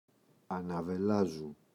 αναβελάζου [anave’lazu]